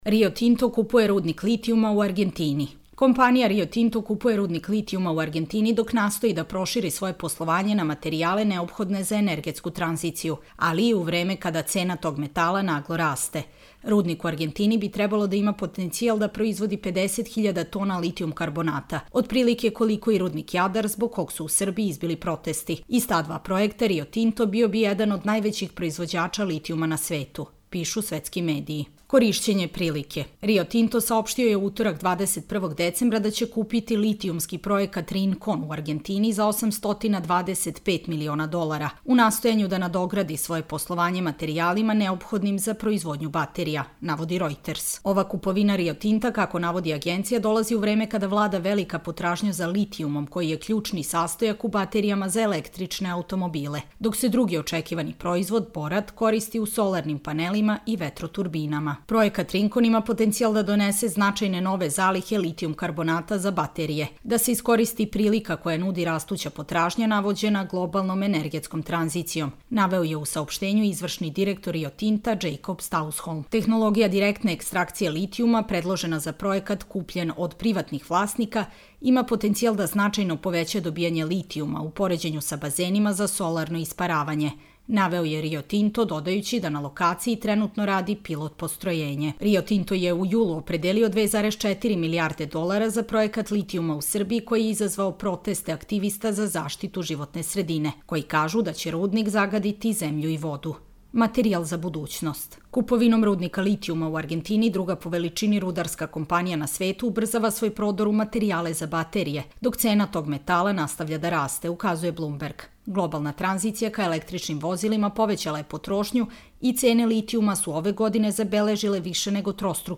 Čitamo vam: Rio Tinto kupuje rudnik litijuma u Argentini